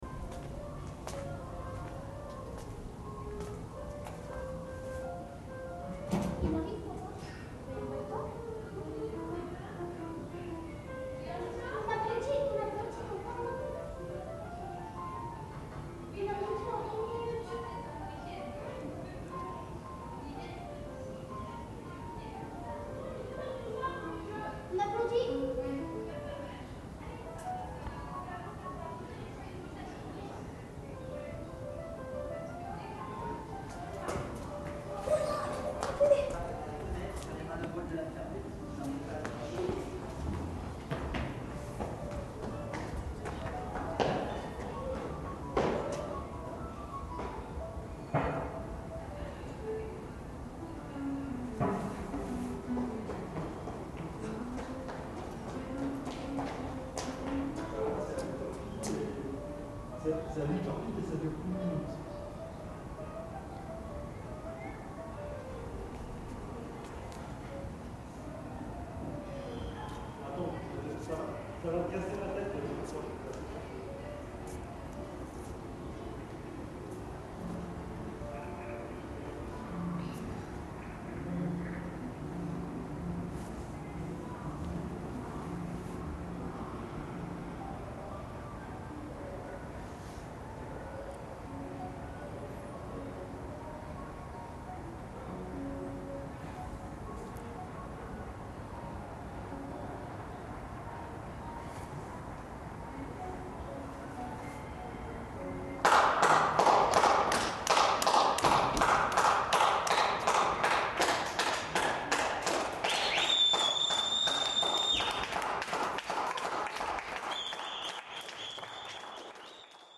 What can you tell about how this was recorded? Paris lockdown sound This is a recording my courtyard on Thursday, March 26, 2020 at 19h58.